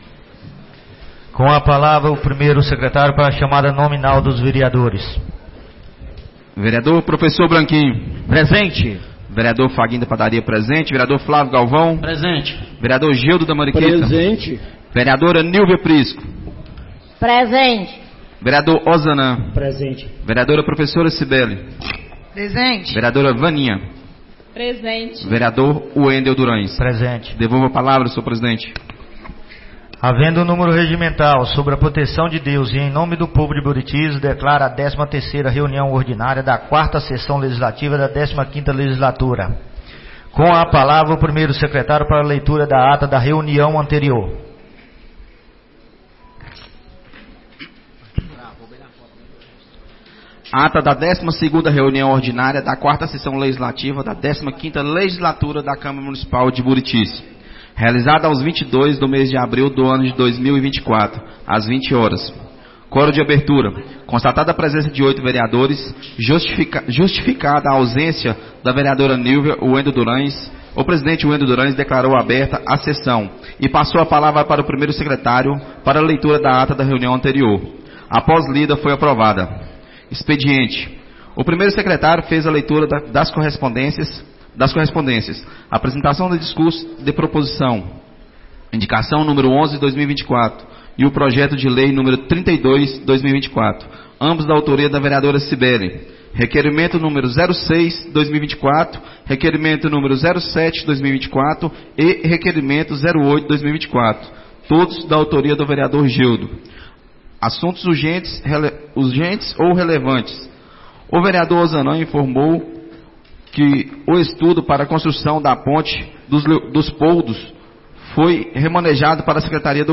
13ª Reunião Ordinária da 4ª Sessão Legislativa da 15ª Legislatura - 29-04-24